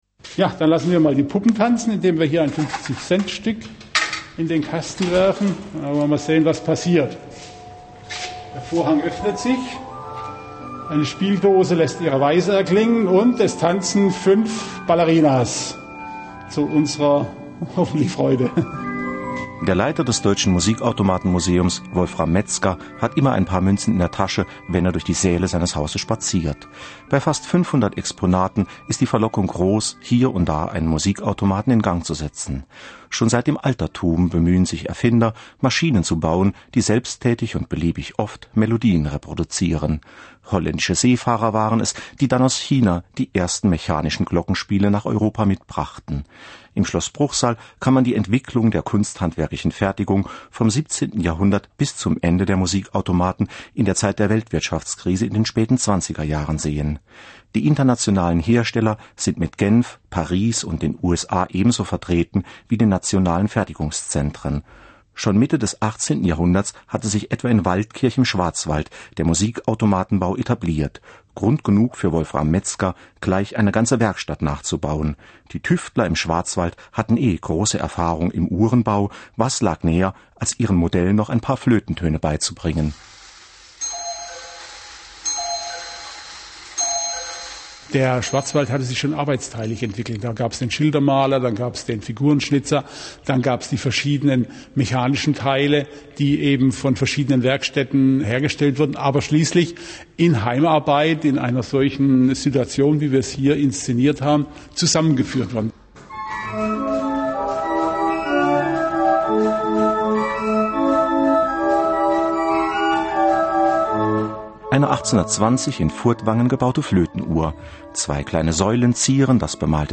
Einführungsvortrag Deutsches Musikautomaten-Museum Bruchsal.
DMM_Bruchsal_Vortrag.mp3